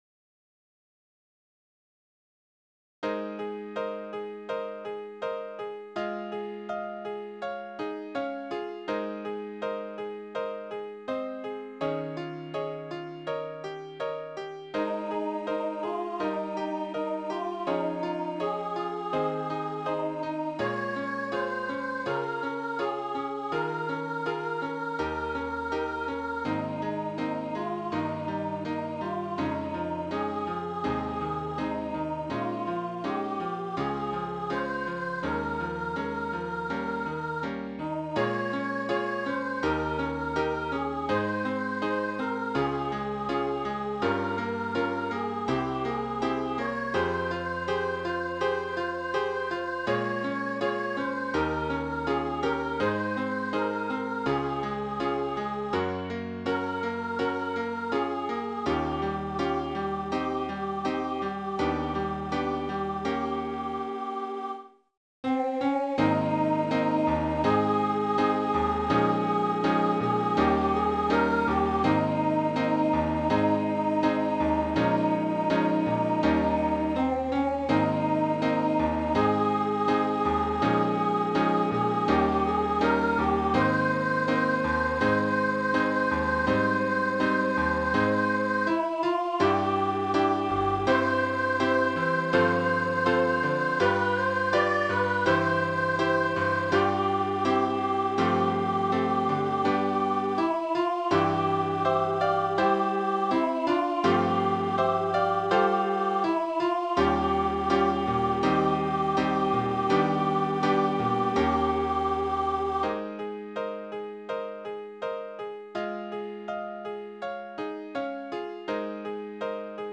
High Voice/Soprano